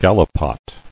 [gal·li·pot]